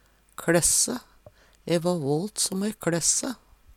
kLæsse - Numedalsmål (en-US)